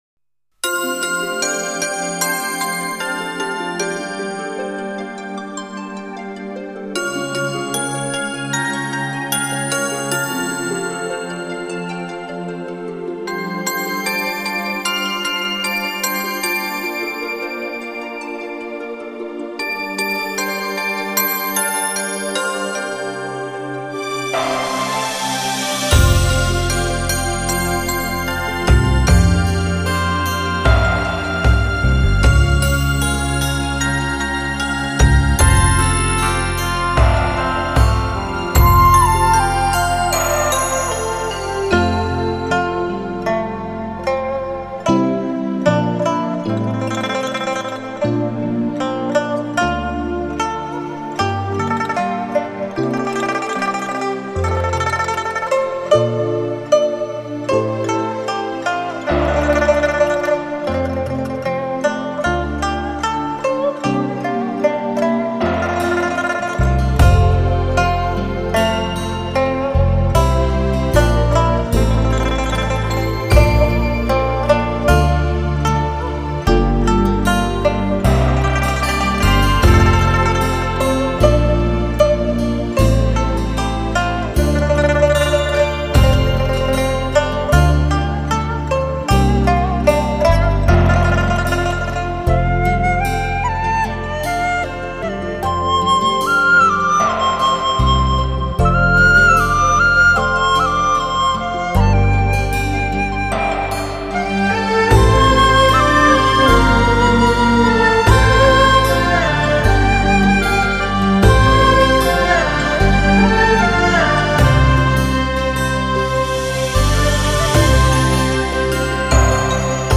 二胡
笛、笙、排箫
古筝
琵琶